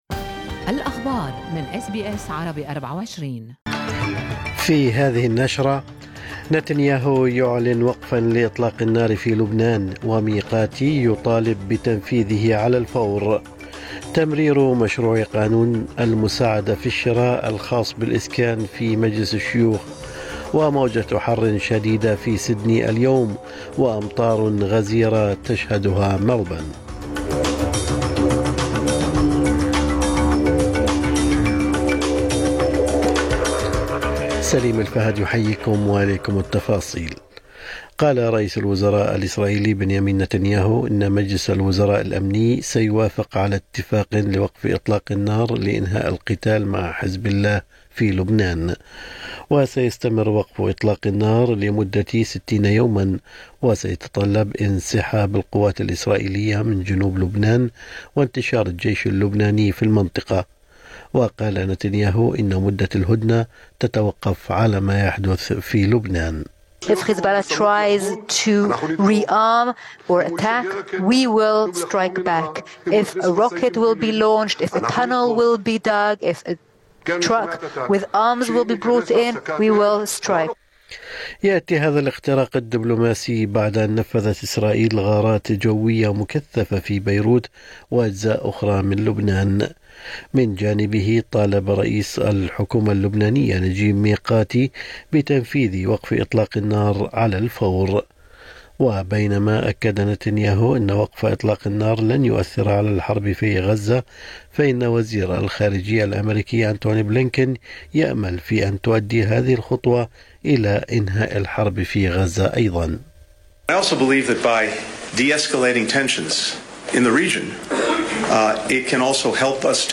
نشرة أخبار الصباح 27/11/2024